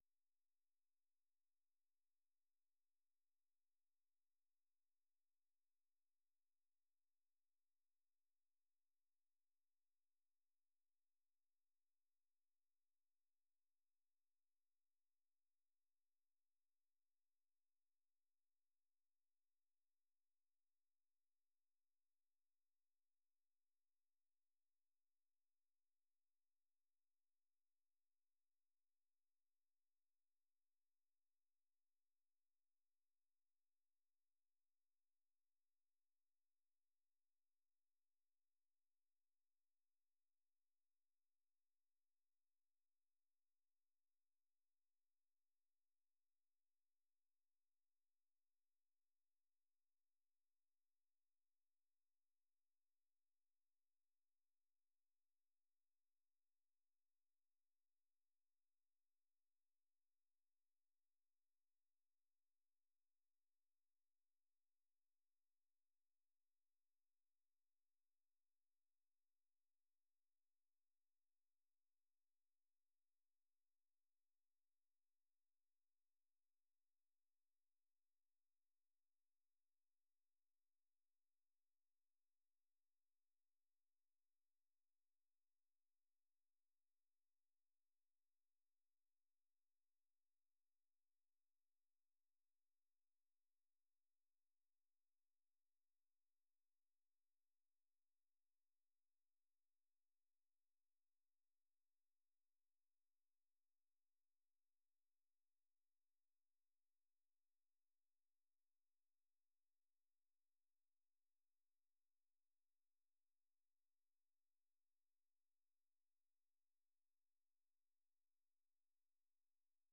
ቅዳሜ፡-ከምሽቱ ሦስት ሰዓት የአማርኛ ዜና